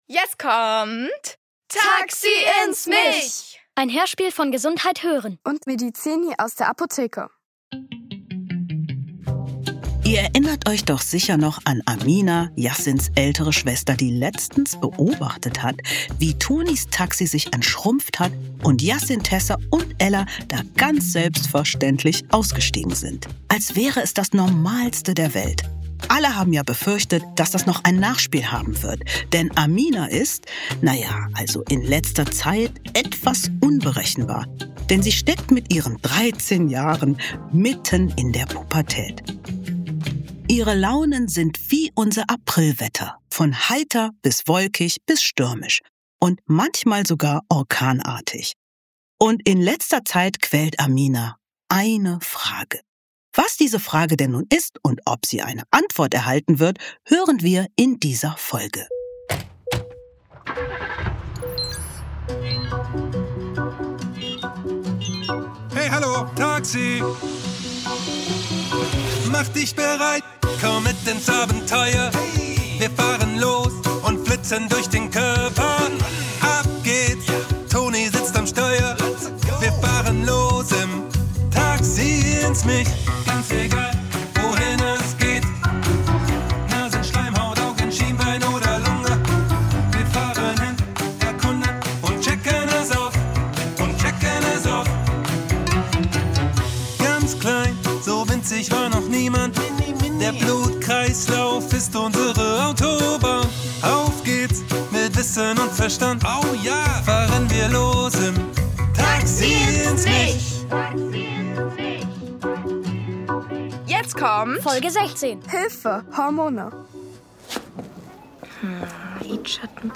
Hilfe! Hormone! ~ Taxi ins Mich | Der Hörspiel-Podcast für Kinder Podcast